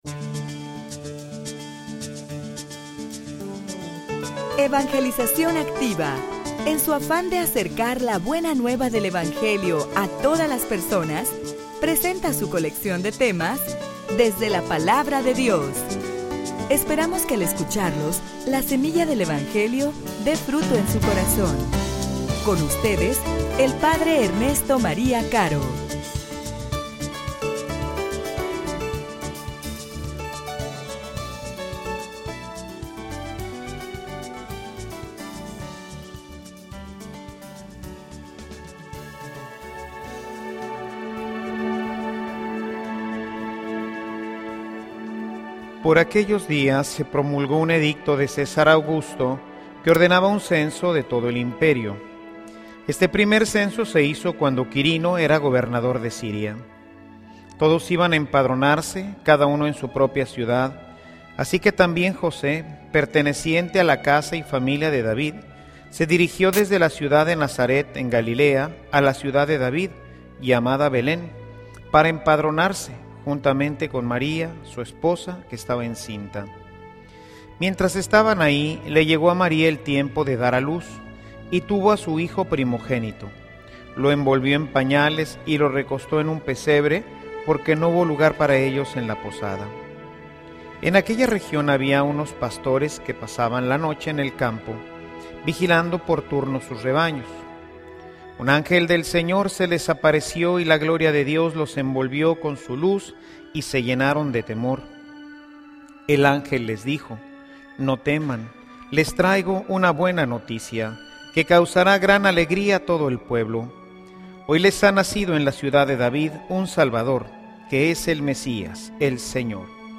homilia_El_Emmanuel_Dios_con_nosotros.mp3